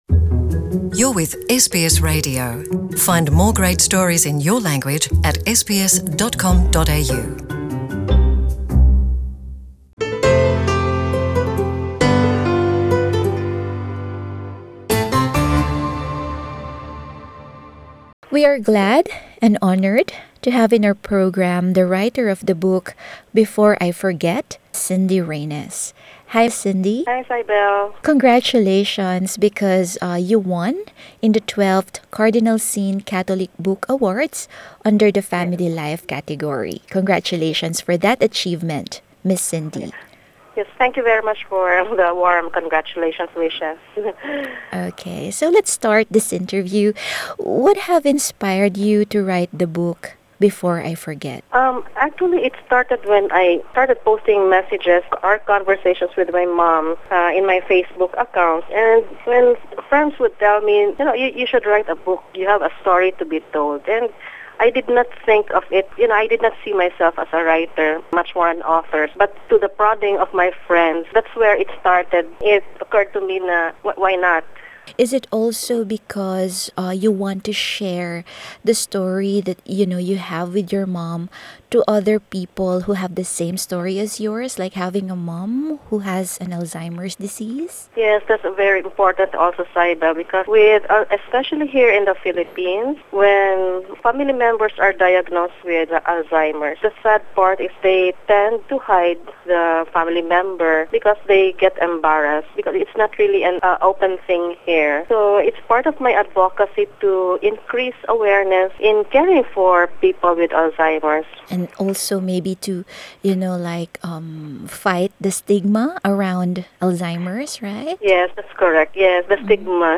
The book, originally published in the Philippines, has its universal appeal touching on the extreme hurdles and pain a primary caregiver meets head-on taking care of a loved one slowly losing her memory. In this exclusive interview